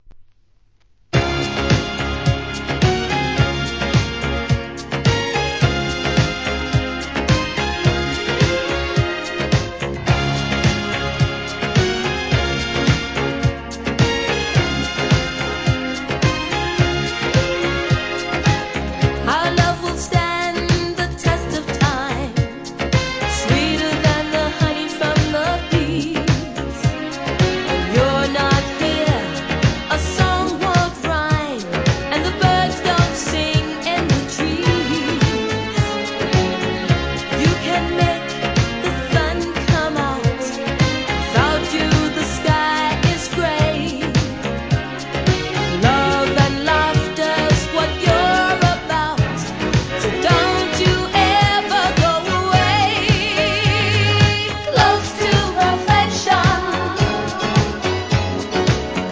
SOUL/FUNK/etc...
爽快なダンスナンバー人気作